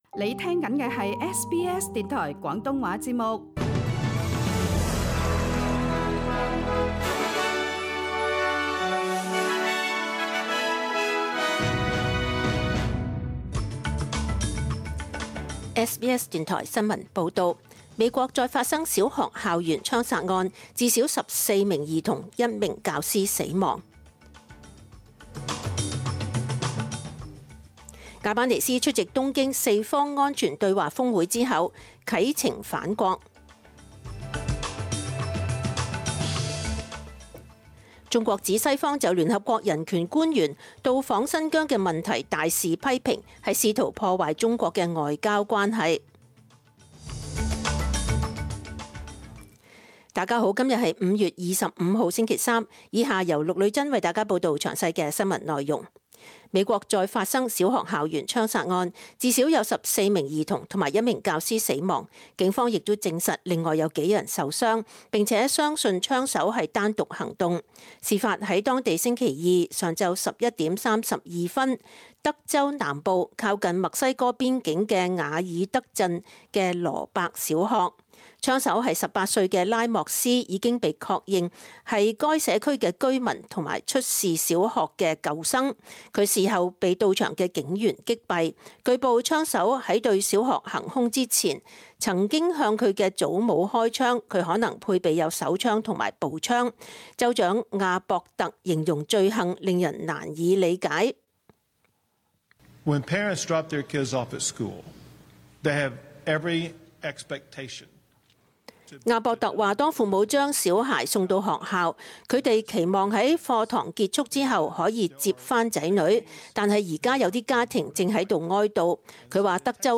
请收听本台为大家准备的详尽早晨新闻。
SBS 廣東話節目中文新聞 Source: SBS Cantonese